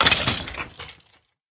sounds / mob / skeleton / death.ogg
death.ogg